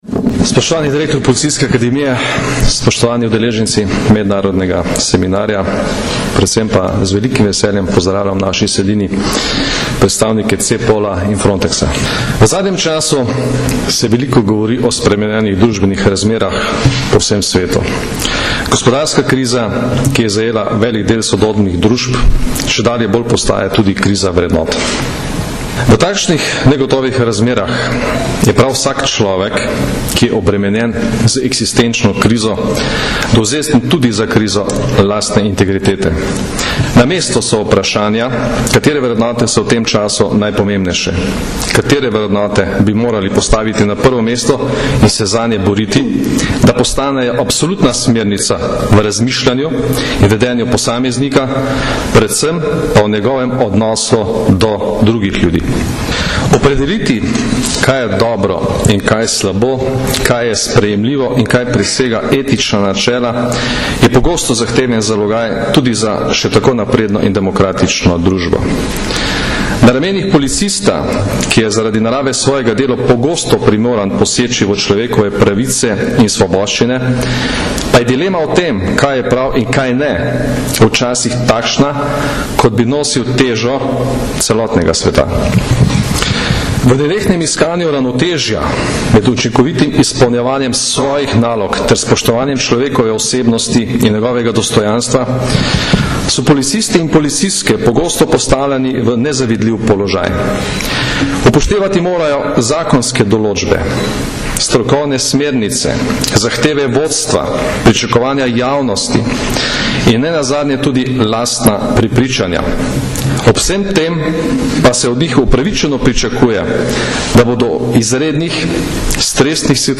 Generalni direktor policije Janko Goršek je danes, 12. aprila 2011, na Brdu pri Kranju s slavnostnim govorom odprl štiridnevno mednarodno konferenco o človekovih pravicah, policijski etiki in integriteti.
Govor generalnega direktorja policije Janka Gorška
Zvočni posnetek govora (mp3)